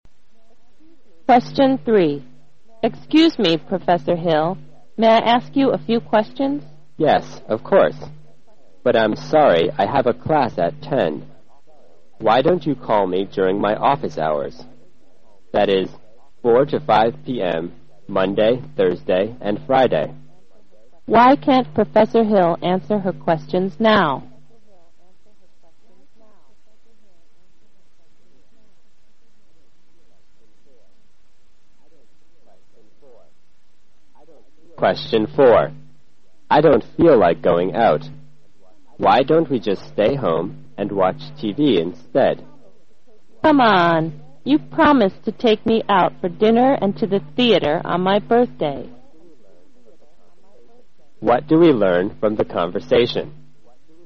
在线英语听力室140的听力文件下载,英语四级听力-短对话-在线英语听力室